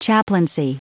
Prononciation du mot chaplaincy en anglais (fichier audio)
Prononciation du mot : chaplaincy
chaplaincy.wav